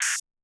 OPENHAT WONDA.wav